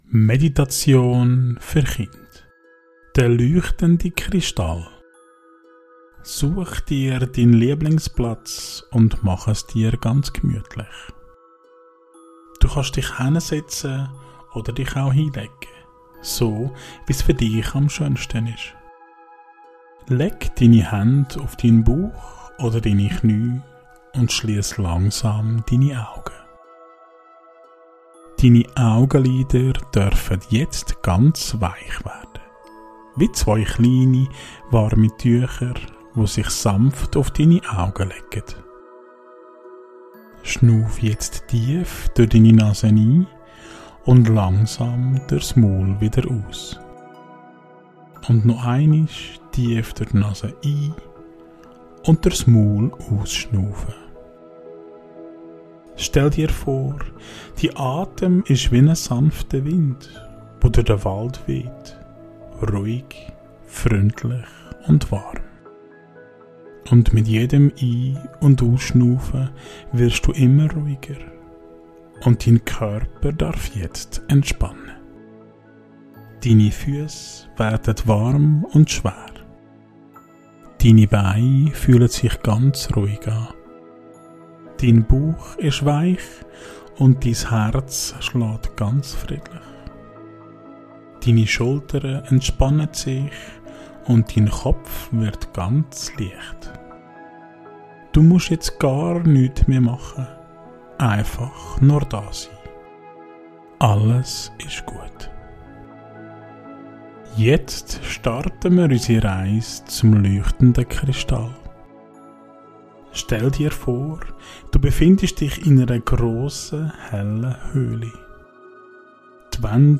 Meditation für Kinder - Der leuchtende Kristall ~ Meine Meditationen Podcast